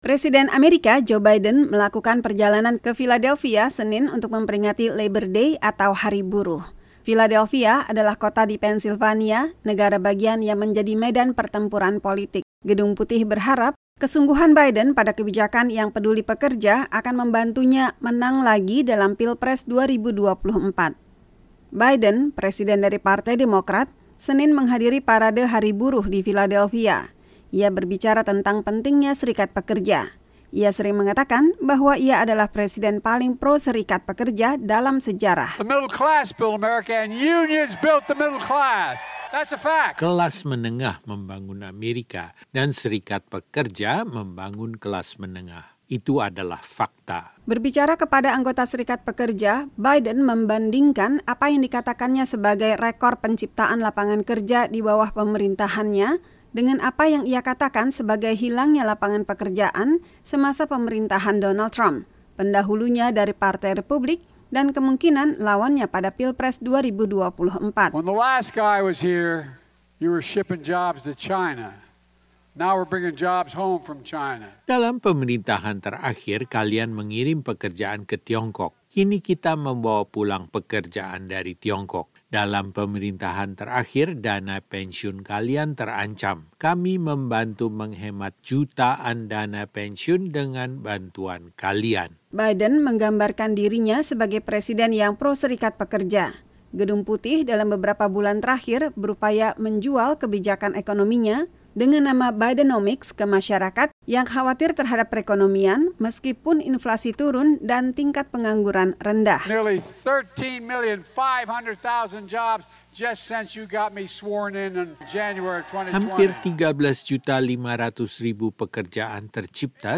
Presiden Amerika Joe Biden berbicara di Philadelphia, Senin (4/9).
Joe Biden, Presiden AS dari Partai Demokrat, menghadiri Parade Hari Buruh di Philadelphia hari Senin (4/9).